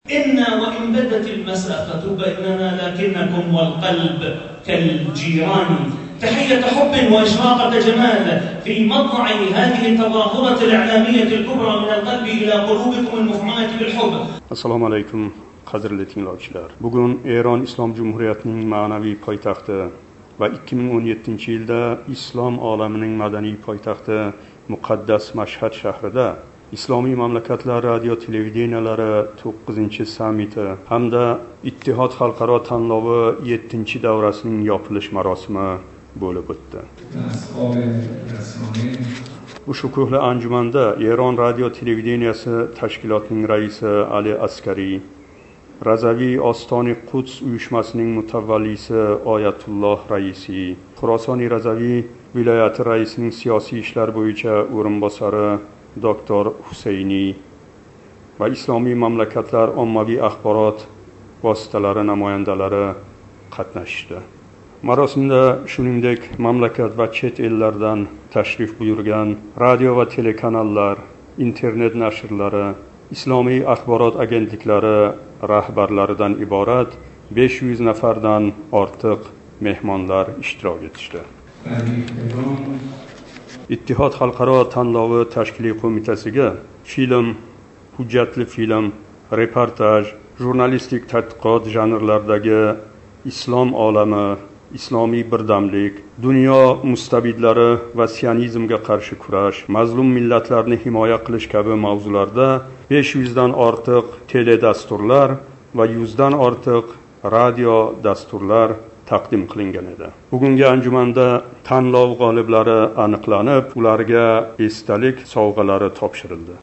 Исломий мамлакатлар радио ва телевидениелари 9-халқаро саммитининг ёпилиш маросимидан репортаж